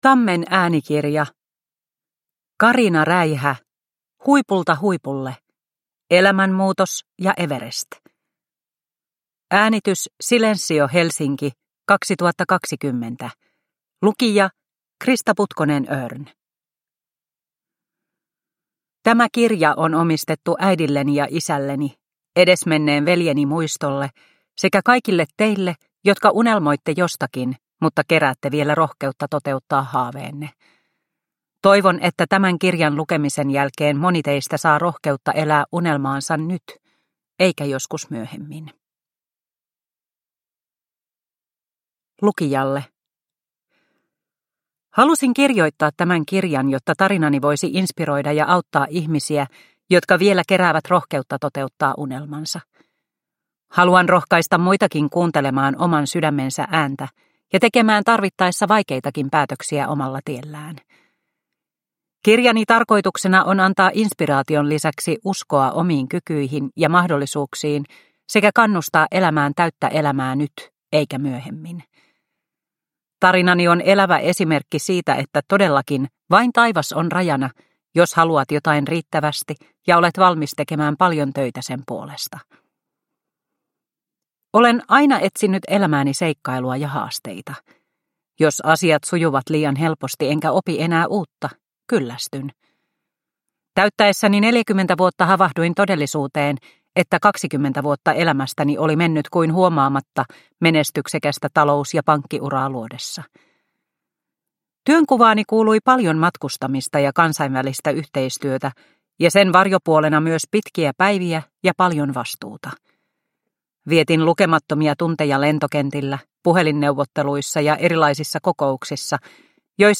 Huipulta huipulle – Ljudbok – Laddas ner